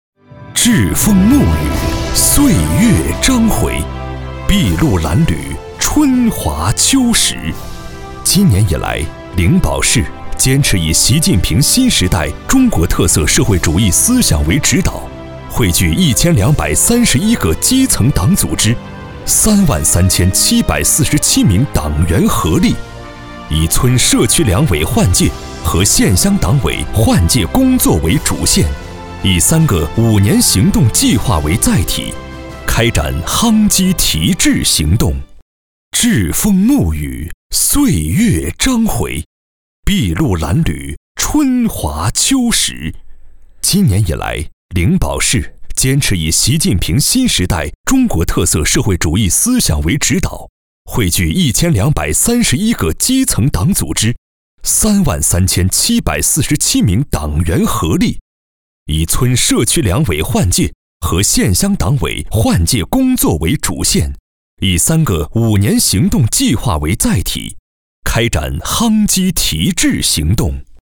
男2号配音师
专题片-男2-党旗飘扬风帆劲.mp3